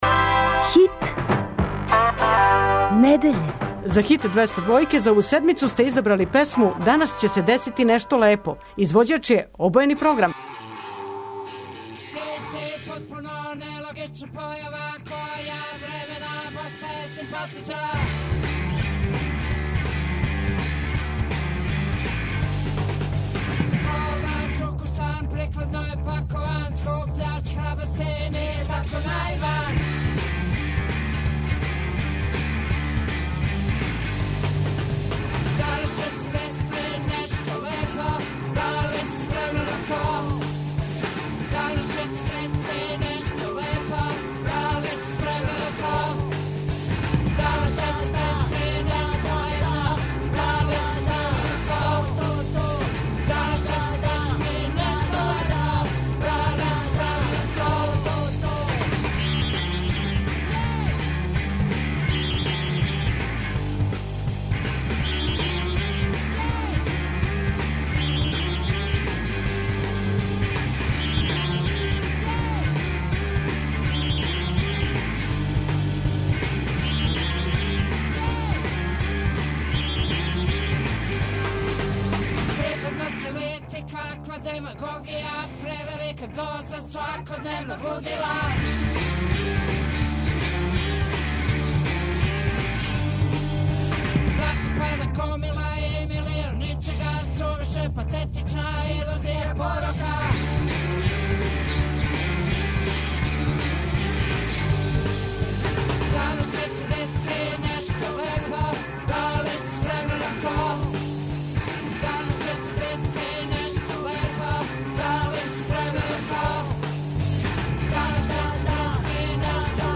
преузми : 55.69 MB Хит недеље Autor: Београд 202 Хит 202, култна емисија 202-јке свакодневно бира хит дана.